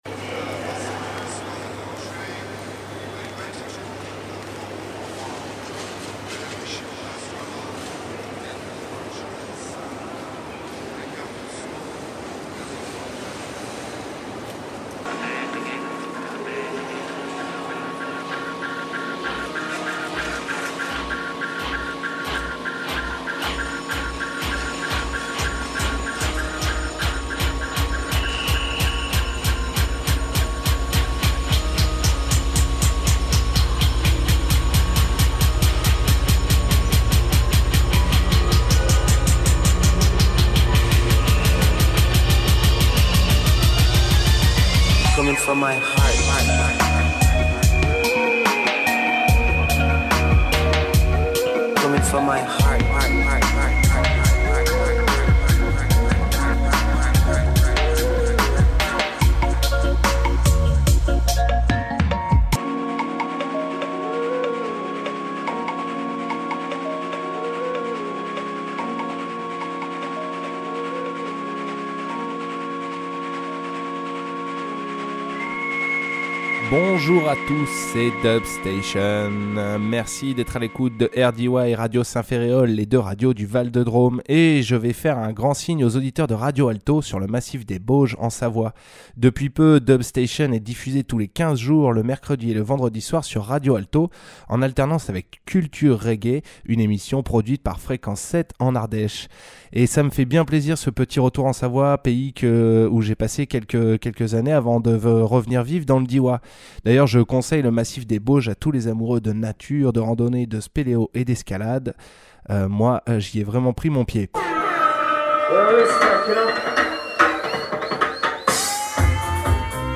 toaster